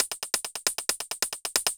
Index of /musicradar/ultimate-hihat-samples/135bpm
UHH_ElectroHatC_135-04.wav